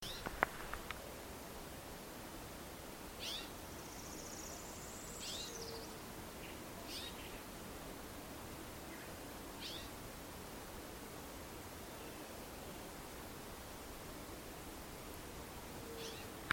Tecelão (Cacicus chrysopterus)
Nome em Inglês: Golden-winged Cacique
Fase da vida: Adulto
Localidade ou área protegida: Parque Provincial Araucaria
Condição: Selvagem
Certeza: Observado, Gravado Vocal